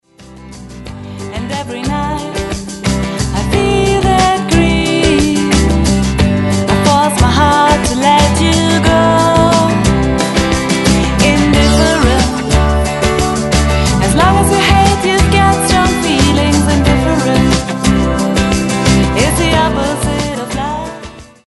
Gesang
Drums
Bass
Keys
Gitarre